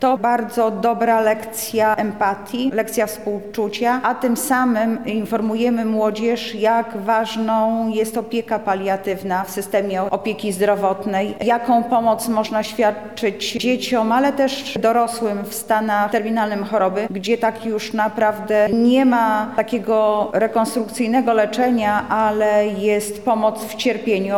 Anna Augustyniak, Zastępca Prezydenta Miasta Lublin ds. Społecznych – mówi Anna Augustyniak, Zastępca Prezydenta Miasta Lublin ds. Społecznych.